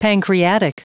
Prononciation du mot pancreatic en anglais (fichier audio)
Prononciation du mot : pancreatic
pancreatic.wav